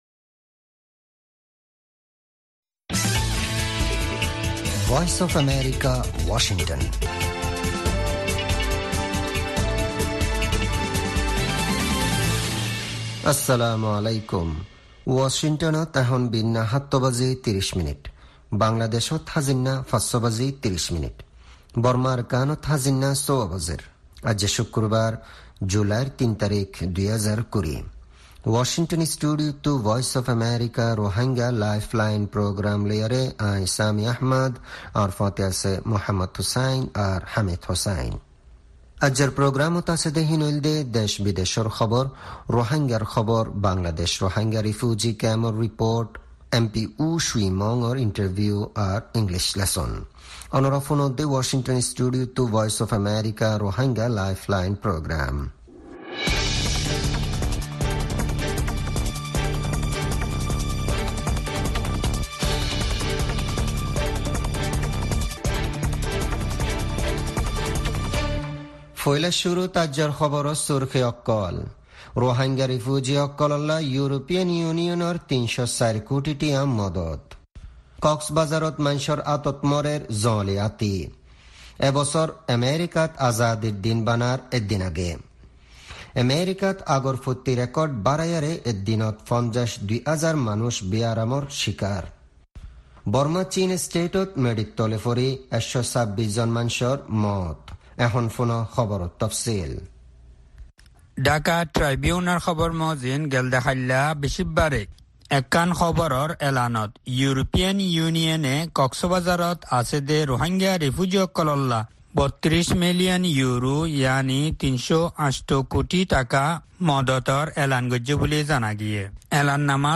Rohingya “Lifeline” radio
News Headlines